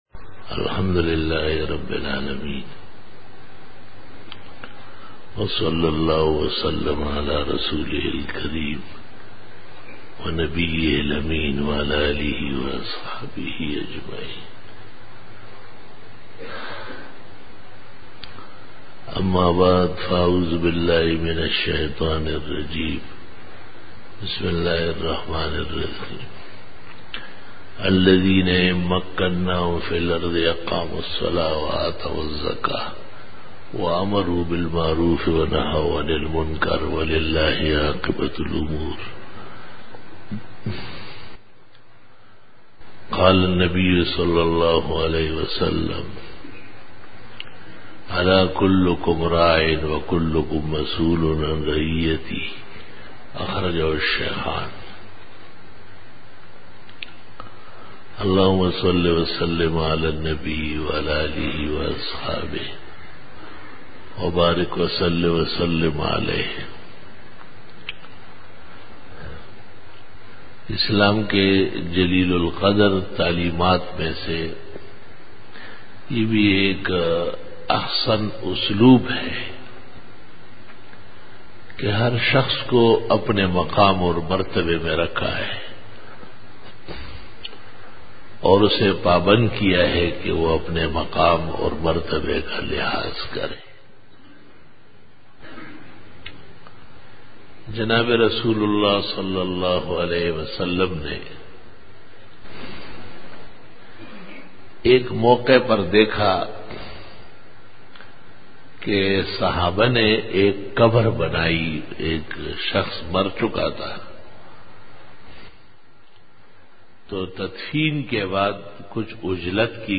05 Bayan e juma tul mubarak 1-feburary-2013